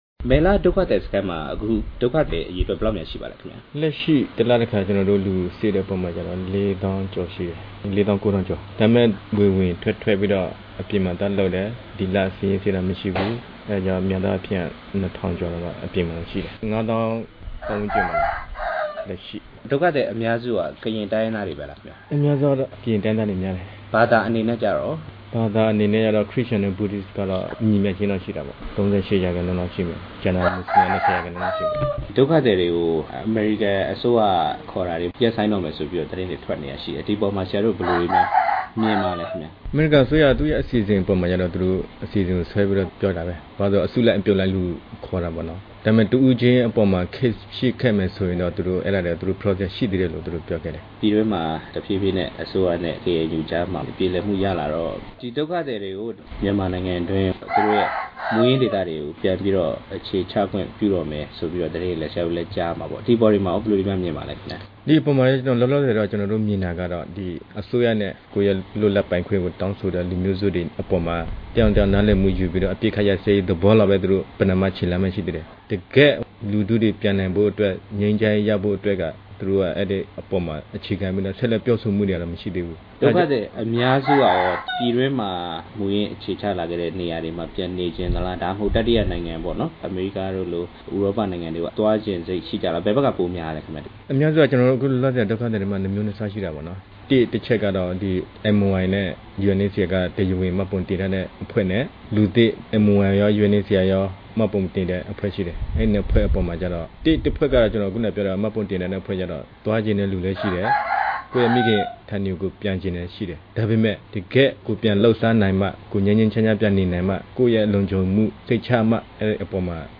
ထိုင်းက မြန်မာဒုက္ခသည်တွေကို မြန်မာနိုင်ငံမှာ ပြန်လည်နေရာချထားမယ့်ကိစ္စ မေးမြန်းချက်